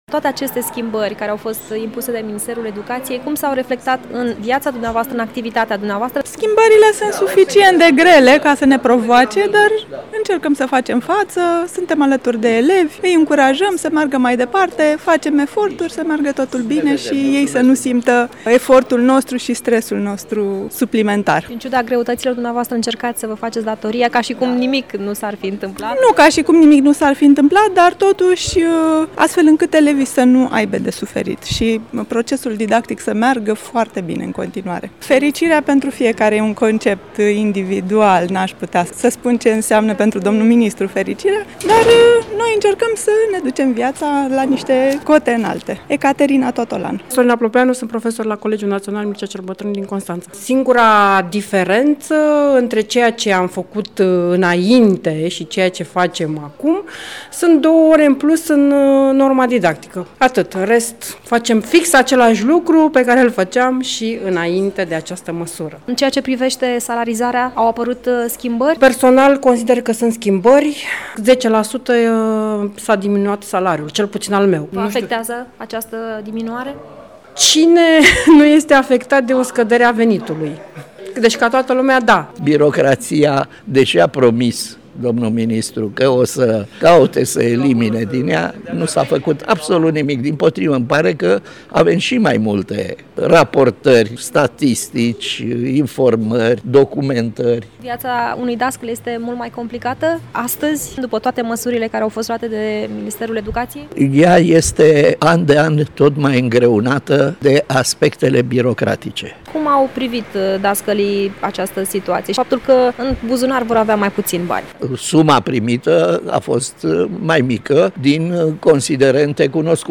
AUDIO | În ciuda nemulțumirilor, mai mulți profesori constănțeni spun că elevii nu trebuie să simtă problemele din sistemul de Educație